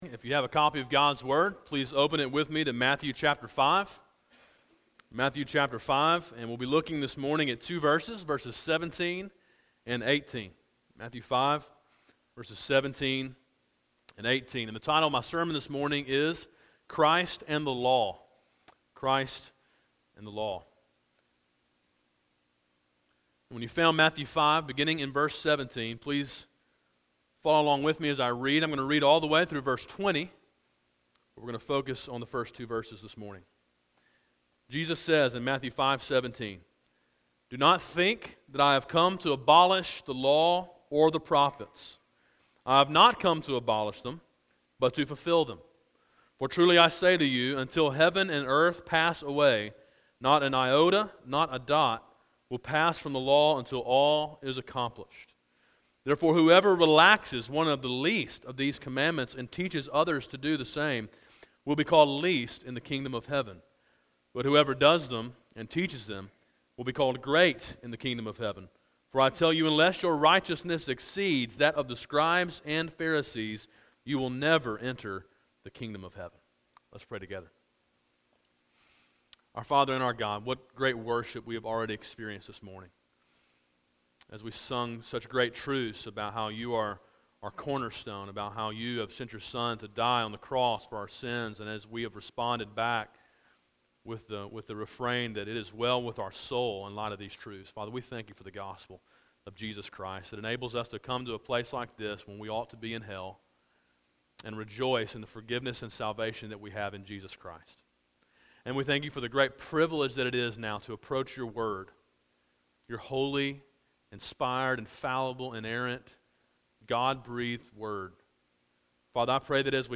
A sermon in a series titled Sermon on the Mount: Gospel Obedience.